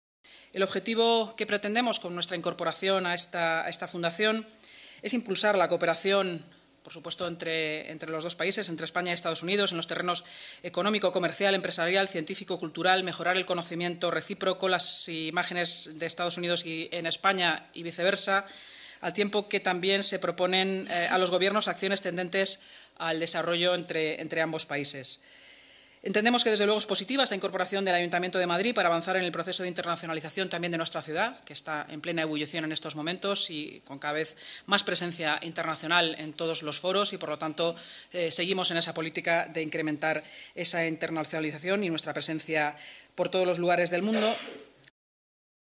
Nueva ventana:Inma Sanz, vicealcaldesa y portavoz municipal